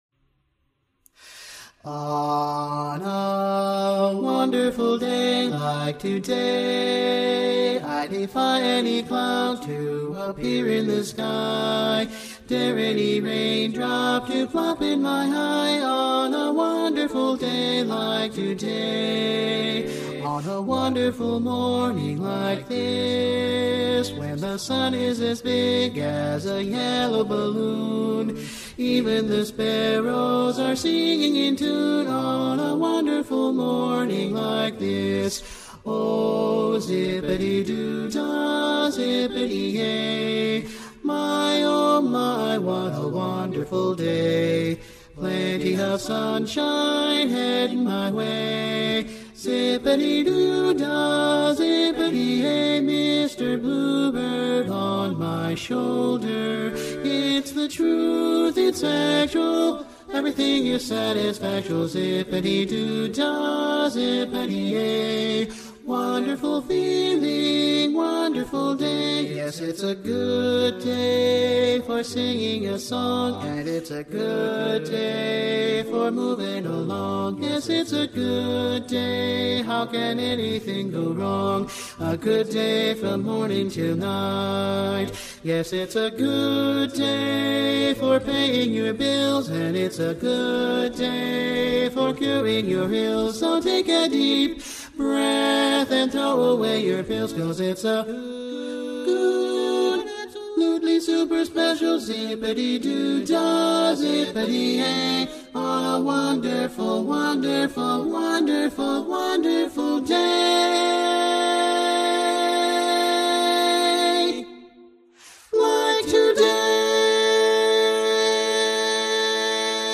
G Major
Lead